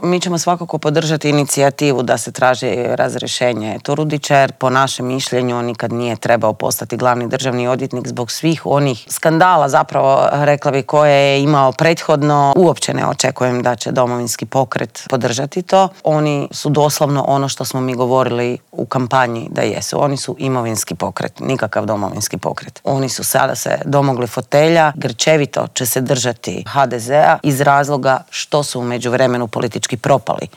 O političkim aktualnostima, nadolazećim lokalnim izborima te o aktualnoj geopolitičkoj situaciji u svijetu razgovarali smo u Intervjuu tjedna Media servisa sa saborskom zastupnicom i koordinatoricom stranke Možemo! Sandrom Benčić.